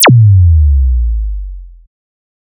Slide Down With Laser{SSO}.wav